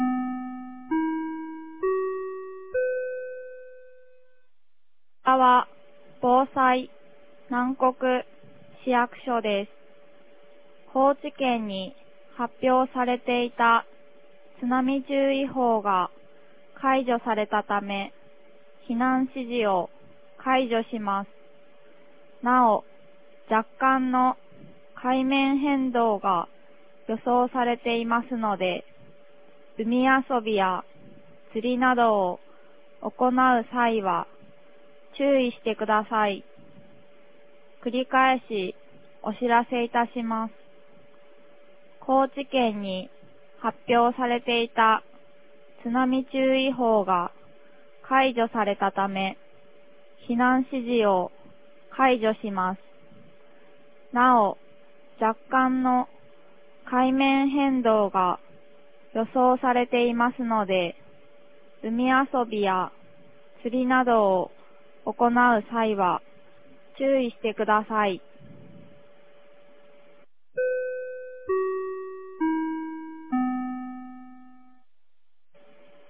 2023年10月09日 12時24分に、南国市より放送がありました。
放送音声